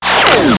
assets/pc/nzp/sounds/weapons/raygun/out.wav at 29b8c66784c22f3ae8770e1e7e6b83291cf27485